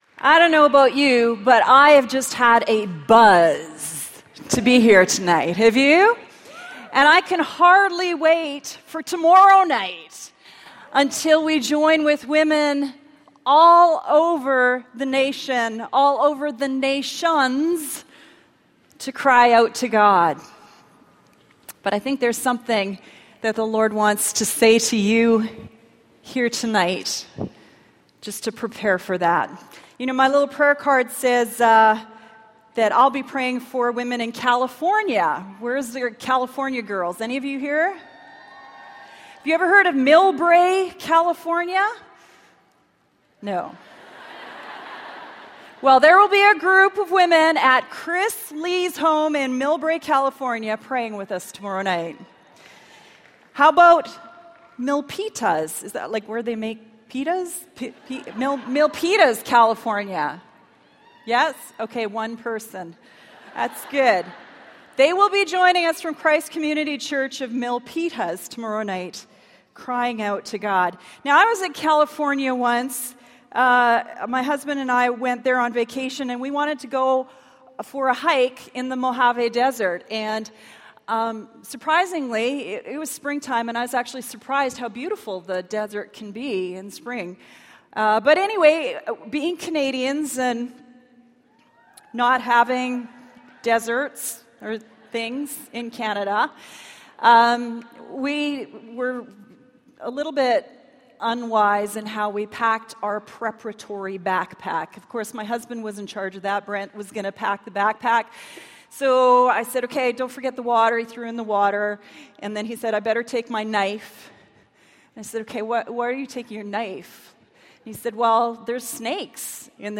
Living from the Bottom of the Barrel | True Woman '16 | Events | Revive Our Hearts